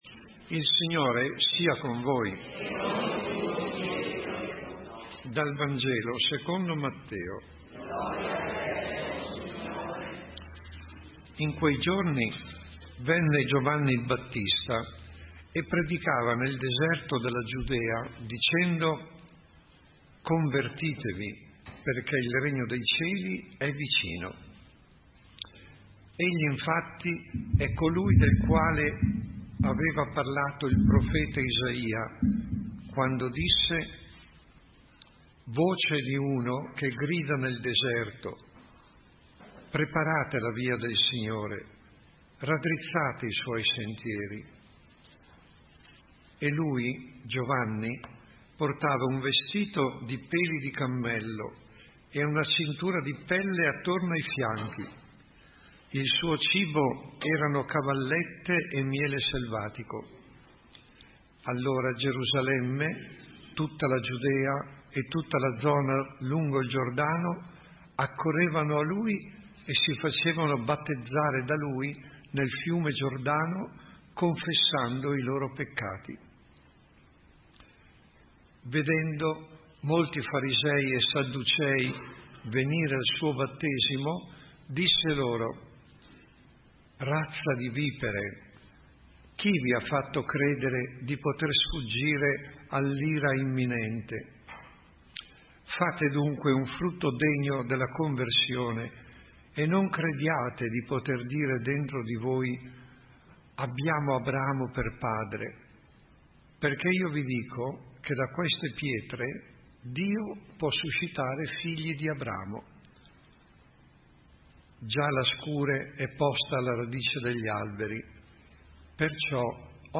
Omelia della II Domenica di Avvento A 04_12_2016.mp3